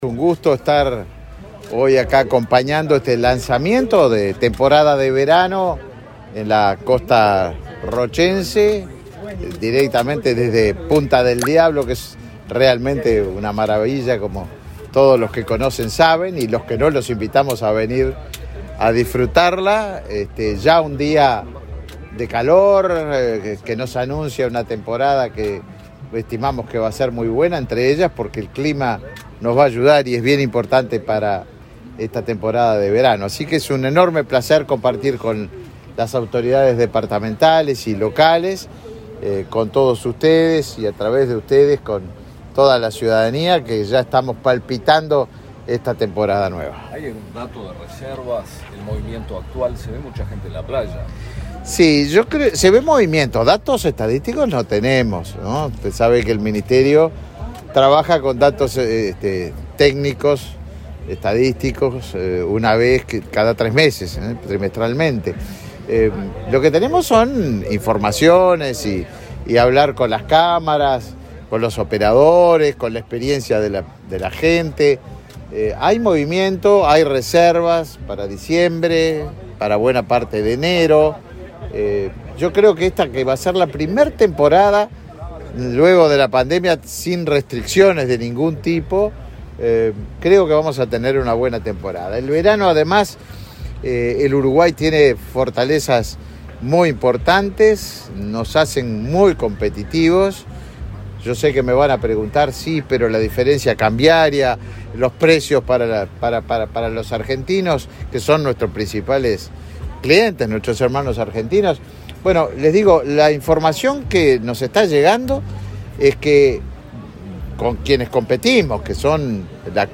Declaraciones a la prensa del ministro de Turismo, Tabaré Viera
Antes dialogó con la prensa.